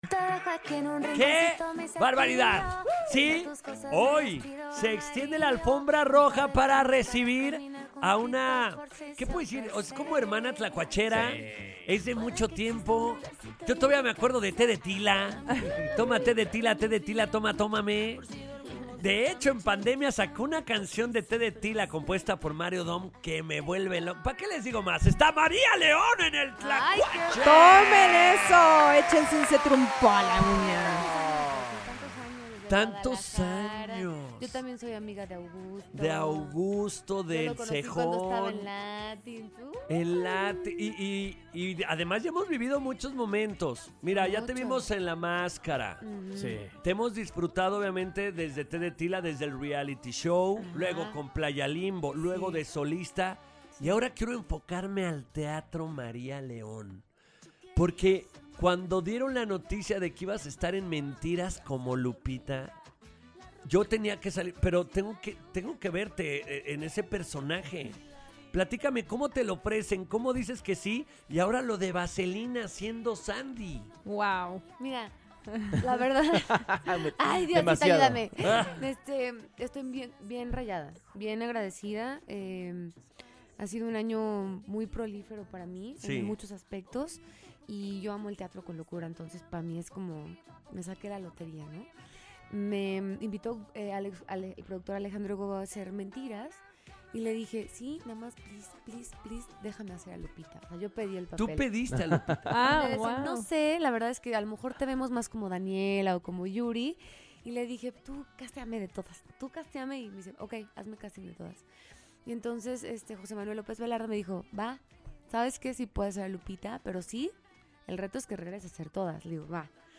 Entrevista exclusiva con María Leon